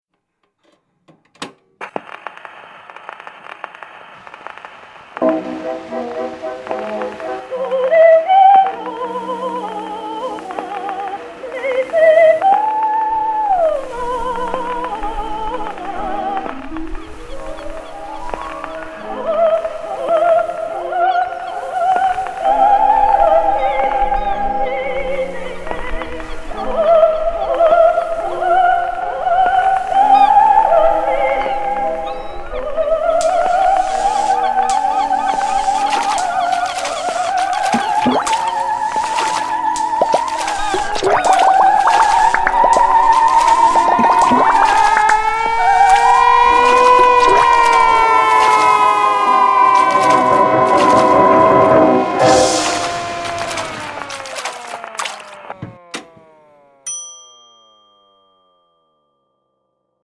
The Siren Original Score and Voice Over